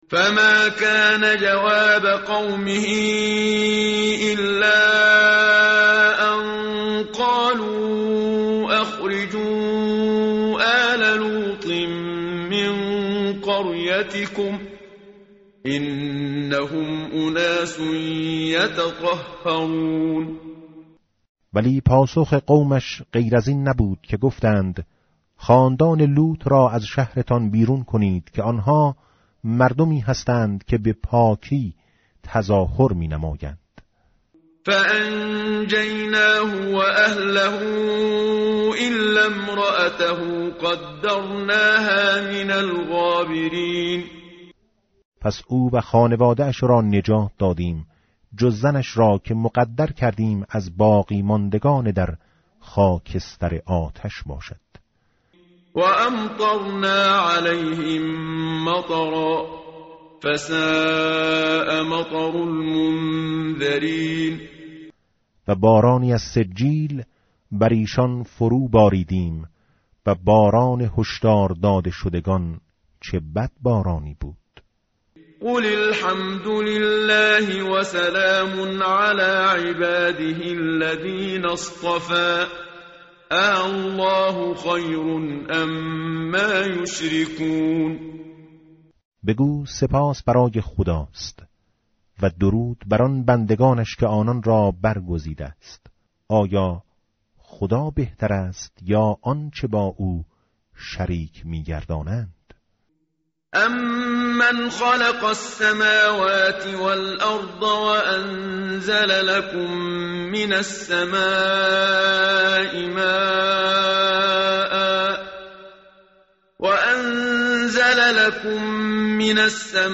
متن قرآن همراه باتلاوت قرآن و ترجمه
tartil_menshavi va tarjome_Page_382.mp3